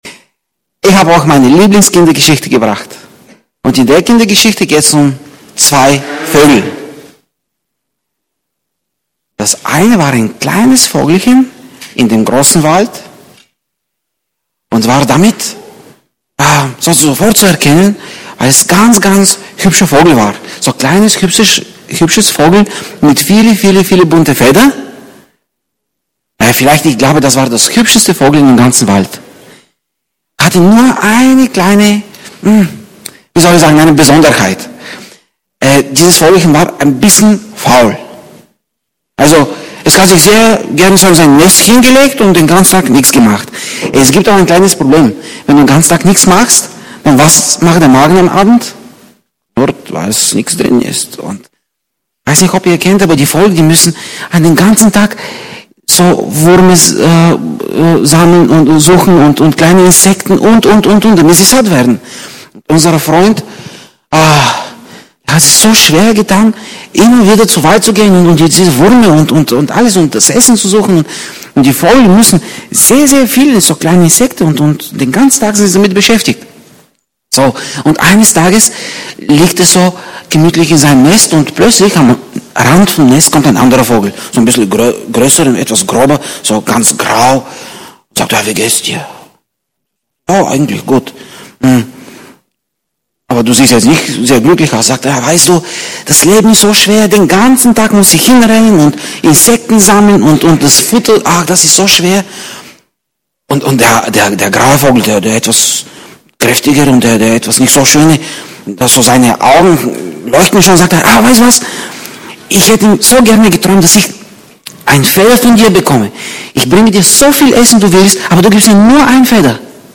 Predigt 07.03.2020